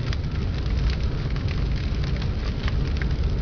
burning1.wav